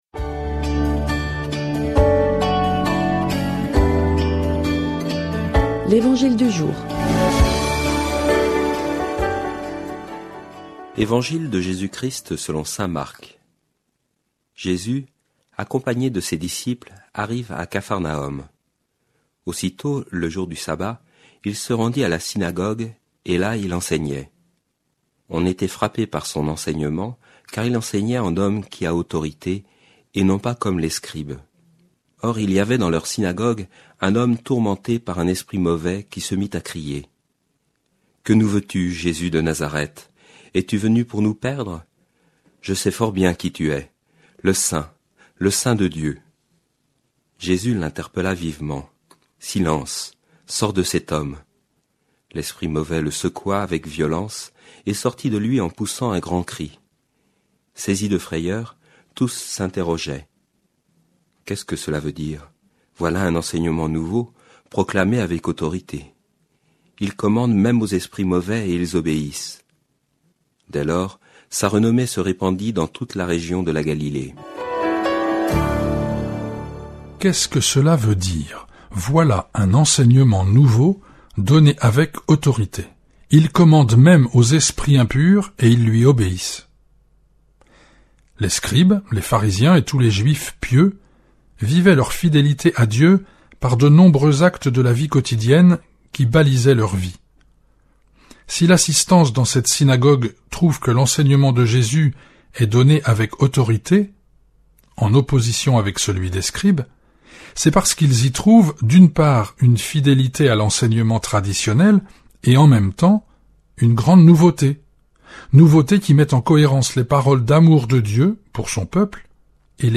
Commentaire diffusé sur Fidélité, radio chrétienne de Nantes.